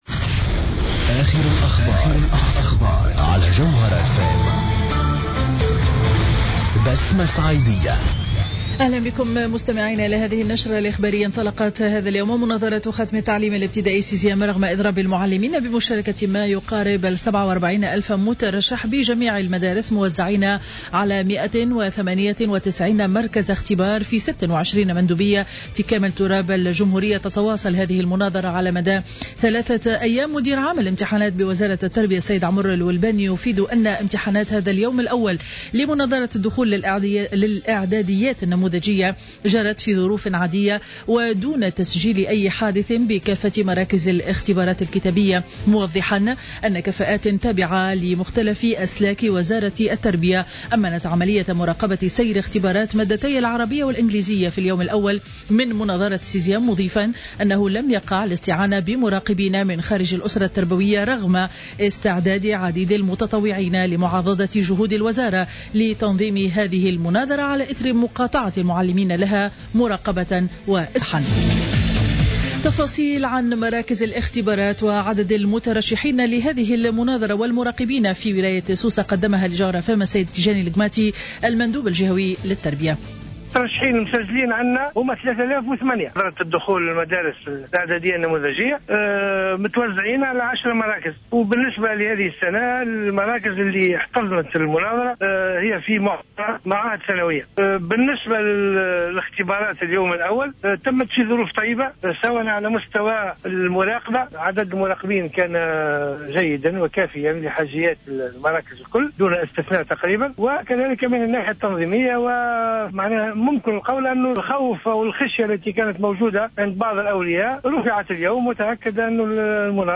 نشرة أخبار منتصف النهار ليوم الخميس 18 جوان 2015